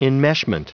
Prononciation du mot enmeshment en anglais (fichier audio)
Prononciation du mot : enmeshment